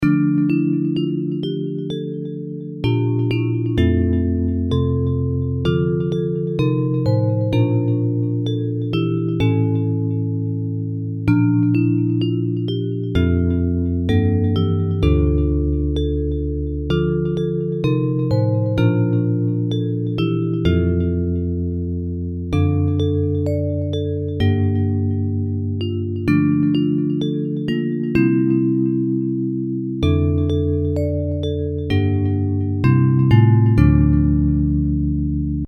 Bells Version
Music by: French carol melody;